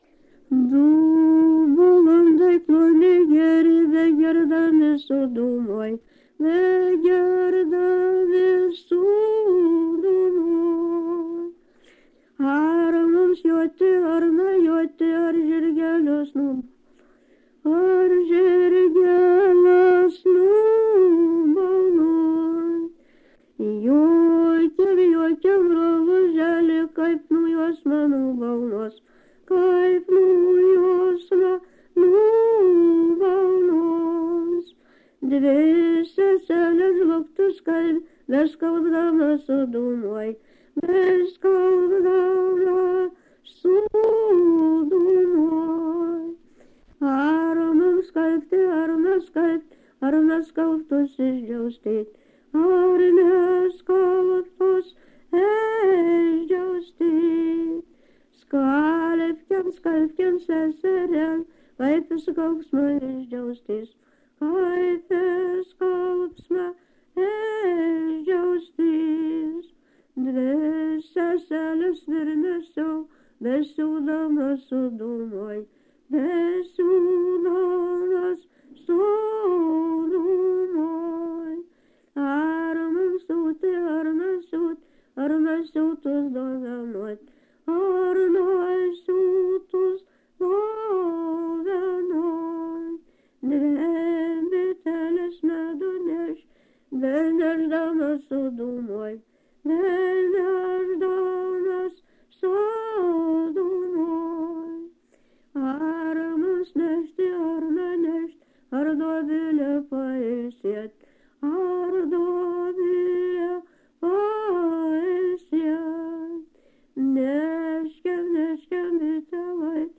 Subject daina
Atlikimo pubūdis vokalinis
Kažkas bando pritarti antro posmo antroj pusėj.